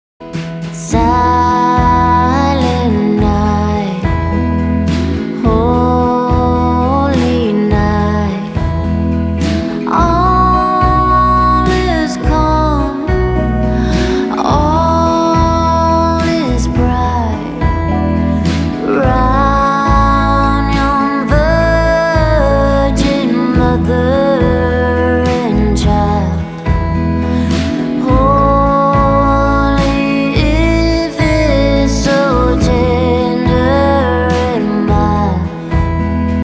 поп
мелодичные
спокойные
праздничные
рождественские